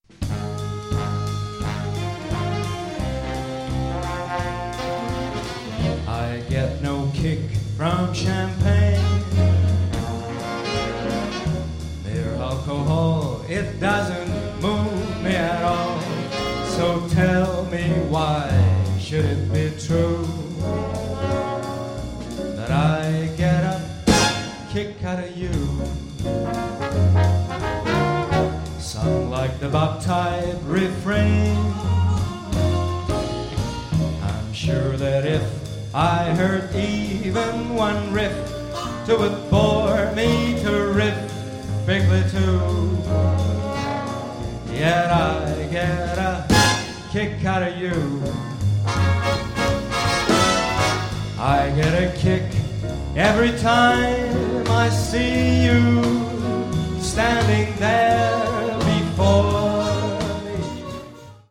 Le registrazioni sono tutte dal vivo
ottima la prova di swing dell'orchestra